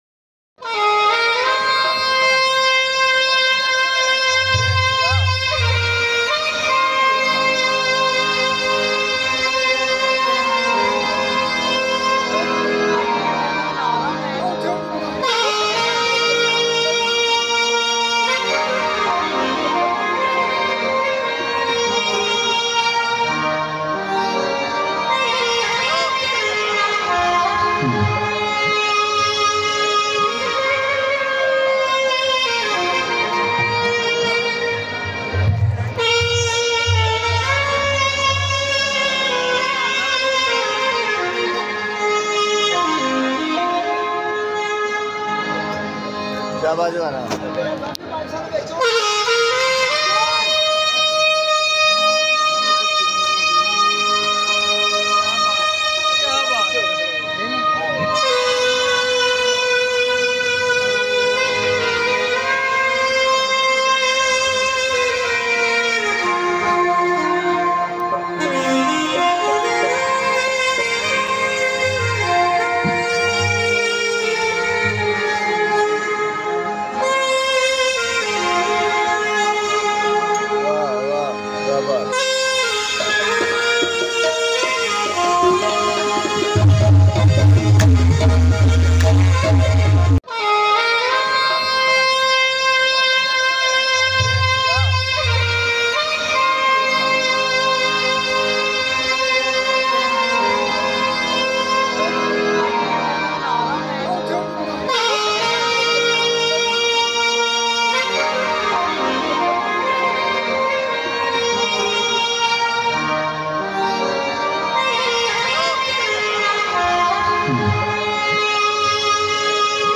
brocken Heart music